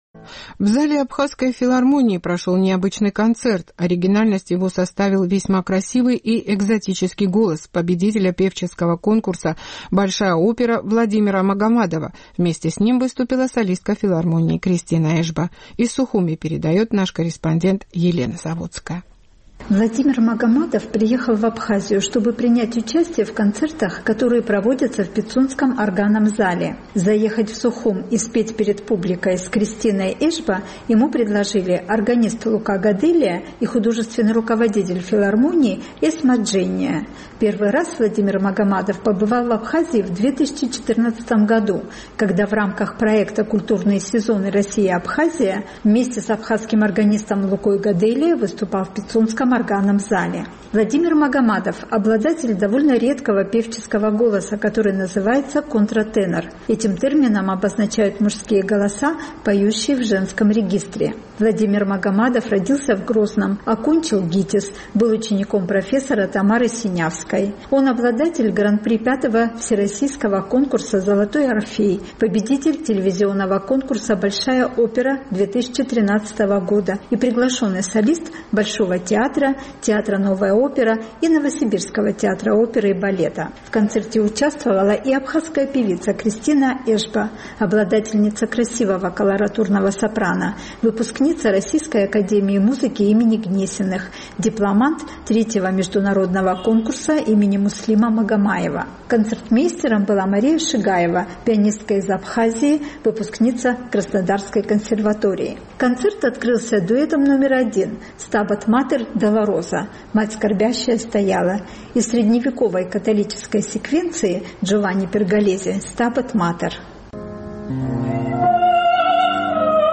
Контратенор в Абхазской филармонии
В зале Абхазской филармонии прошел необычный концерт.